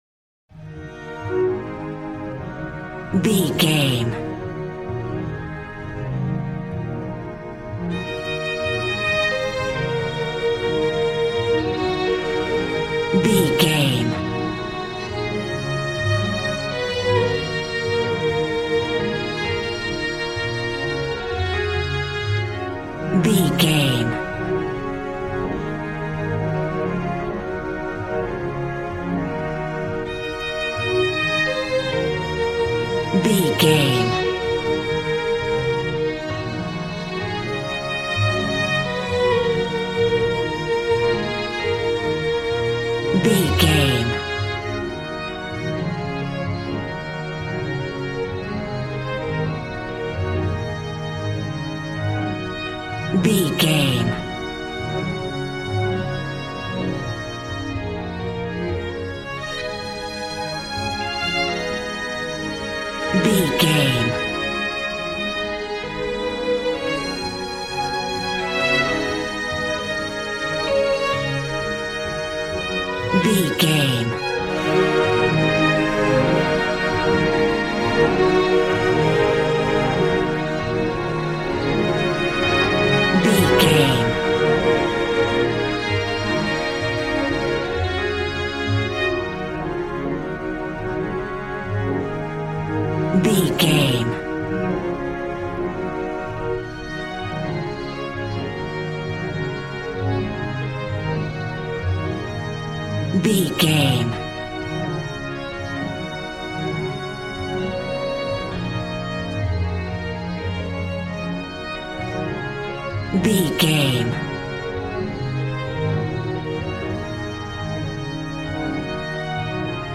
Aeolian/Minor
E♭
joyful
conga